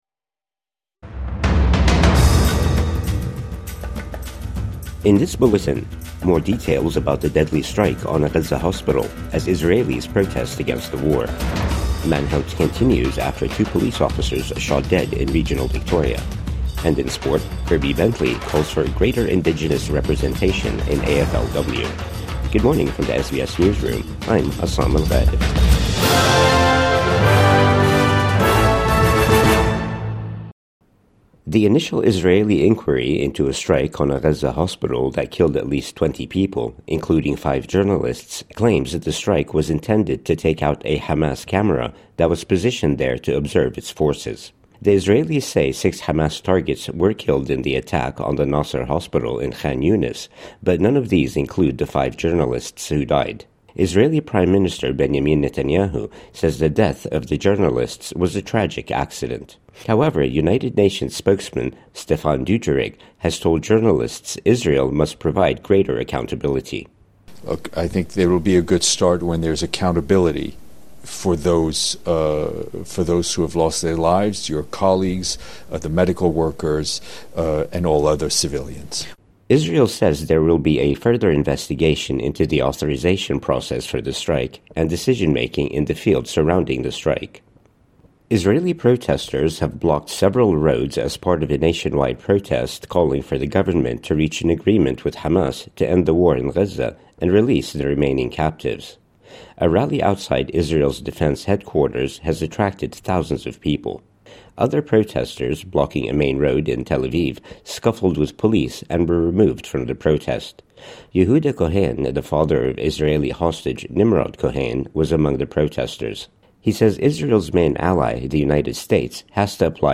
More details about the deadly strike on a Gaza hospital | Morning News Bulletin 27 August 2025